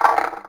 Perc [ Monster ].wav